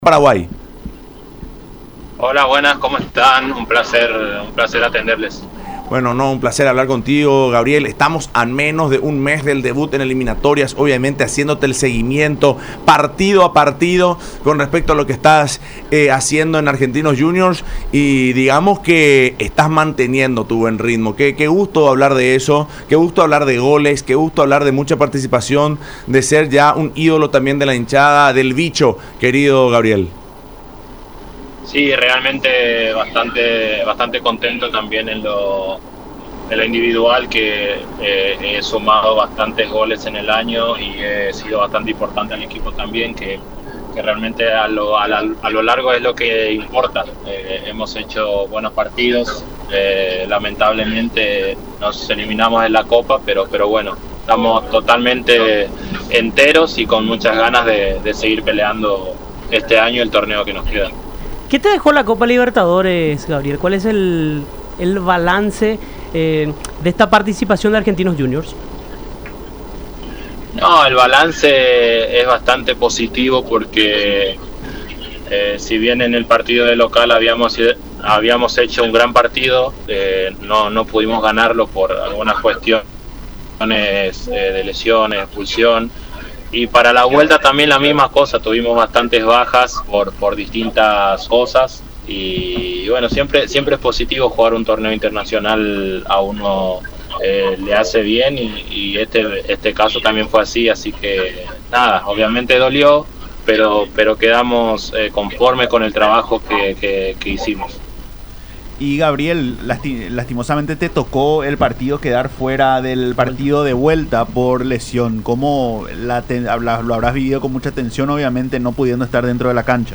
En contacto con Fútbol Club, a través de radio la Unión y Unión TV, explicó que hay jugadores de excelente nivel para alcanzar el objetivo.